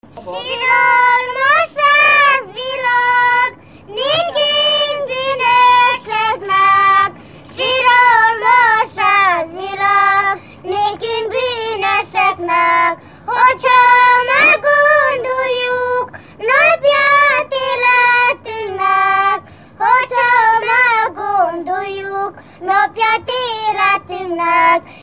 Moldva és Bukovina - Moldva - Lujzikalagor
Műfaj: Húsvéti ének
Stílus: 5. Rákóczi dallamkör és fríg környezete
Szótagszám: 7.6.7.6
Kadencia: 5 (b3) 2 1